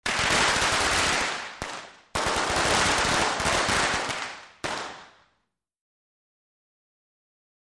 Roll Of Firecrackers, W Quick Multiple Explosions, Interior Perspective.